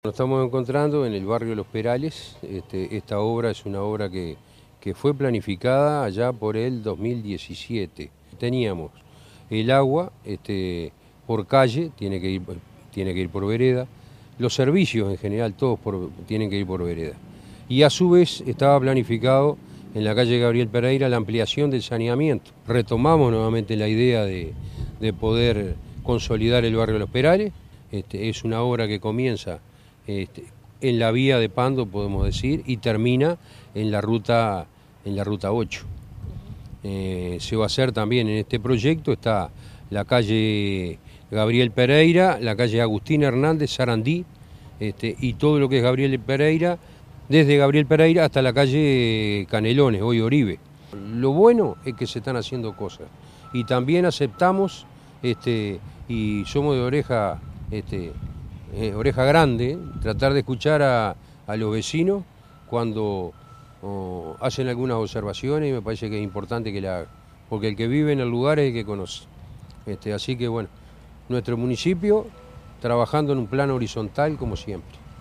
alcalde_de_pando_alcides_perez.mp3